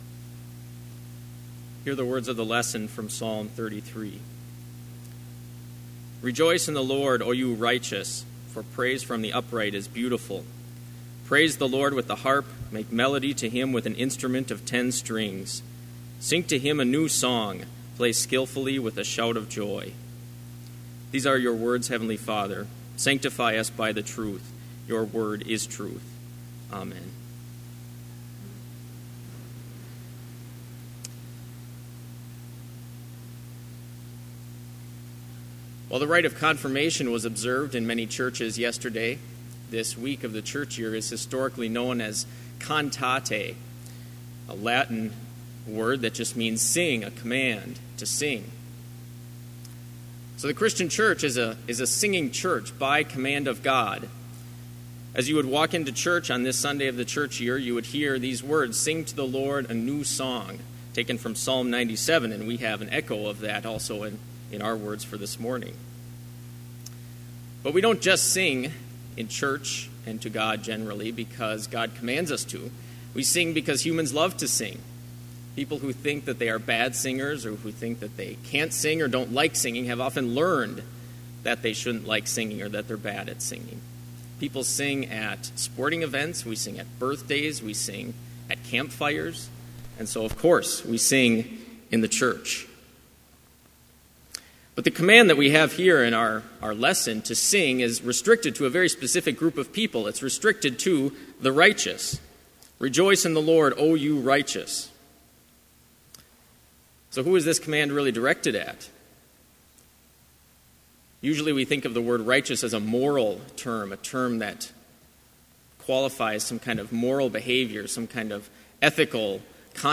Sermon Only
This Chapel Service was held in Trinity Chapel at Bethany Lutheran College on Monday, April 30, 2018, at 10 a.m. Page and hymn numbers are from the Evangelical Lutheran Hymnary.